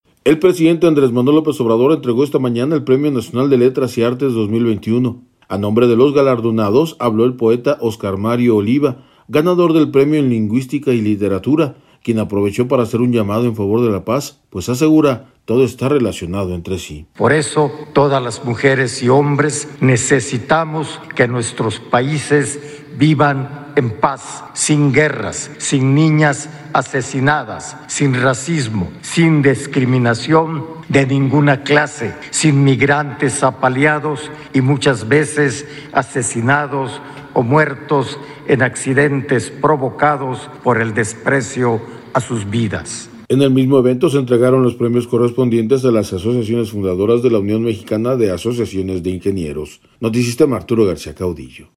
El presidente Andrés Manuel López Obrador entregó esta mañana el Premio Nacional de Letras y Artes 2021. A nombre de los galardonados habló el poeta Oscar Mario Oliva (en la foto), ganador del premio en Lingüística y Literatura, quien aprovechó para hacer un llamado en favor de la paz, pues asegura, todo está relacionado entre sí.